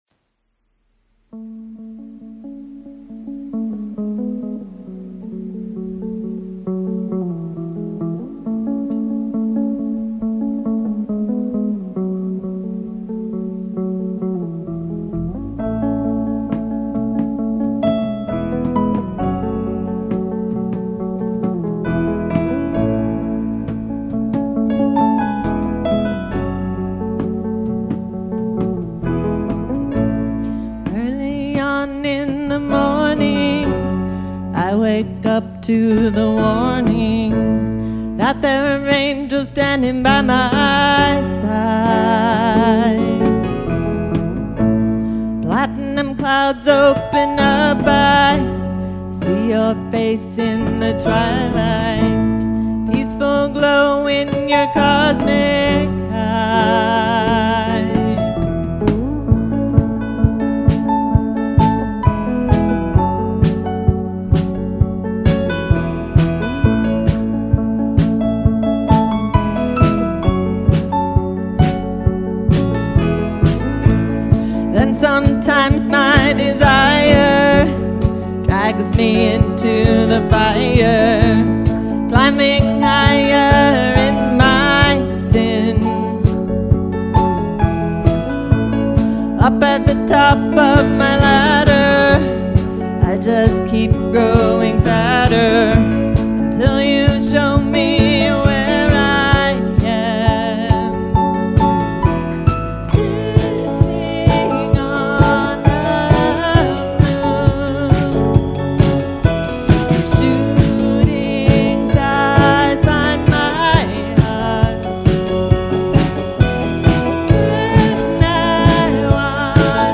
In this conversation